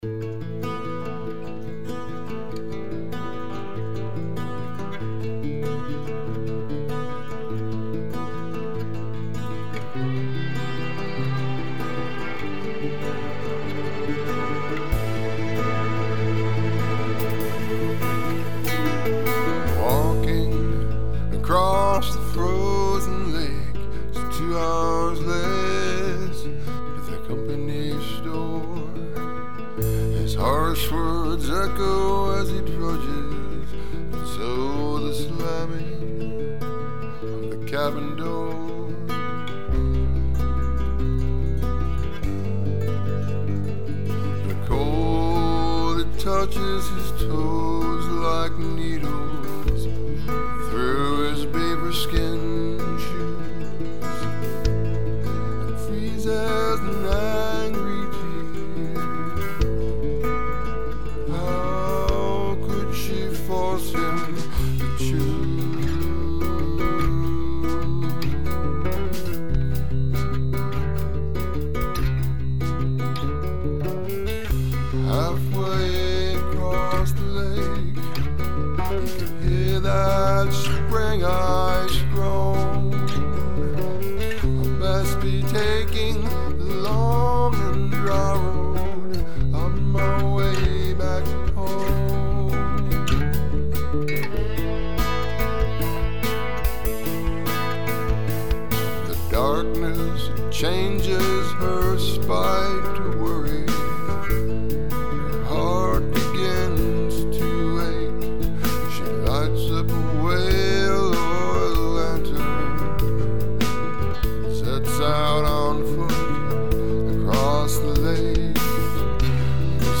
Bass
Rode condenser
Slide Guitar
Strings